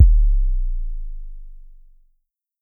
SouthSide Kick Edited (39).wav